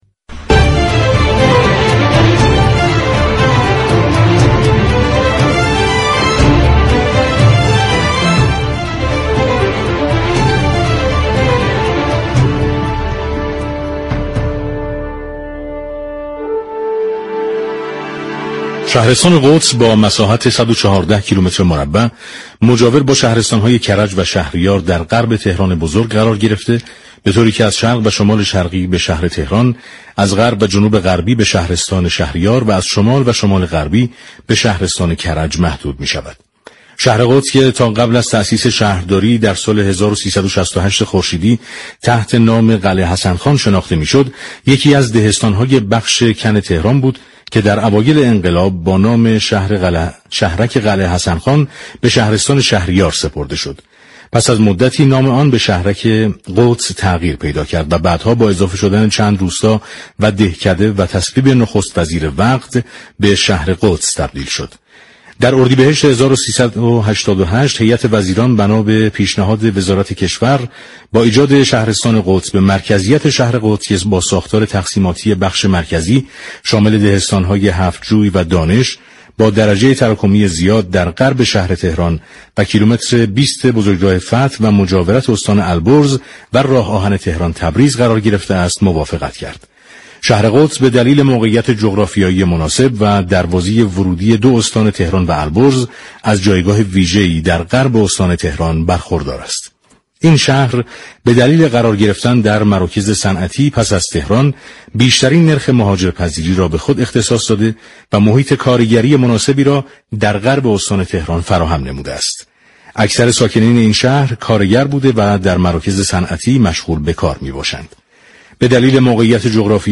برنامه پل مدیریت 8 اسفند با سعید شهلی شهردار شهر قدس درباره اقدامات شهرداری و چالش‌ها و فرصت‌های شهر قدس گفت و گو كرد.